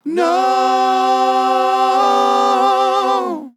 Tags: beatbox, Boots And Cats, dry, GROUP, male, NOOO, sample, sfx, sounds